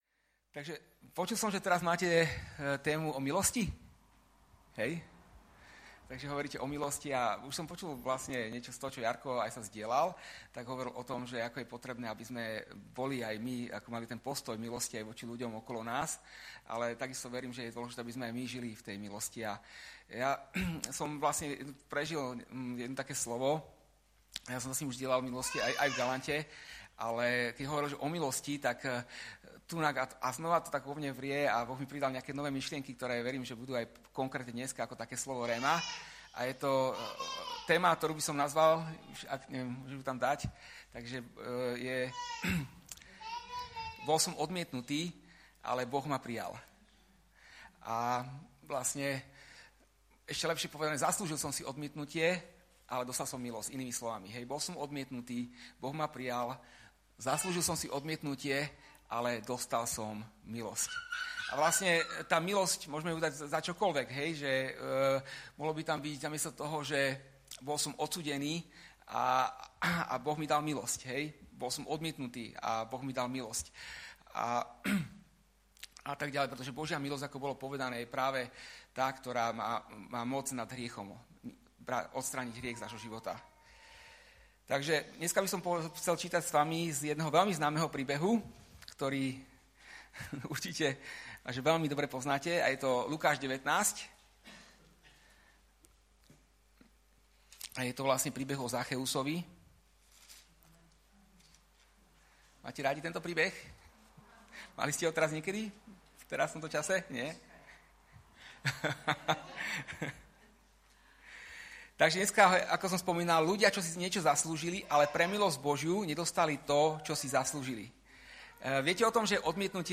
Vydané: 2019 Žáner: kázeň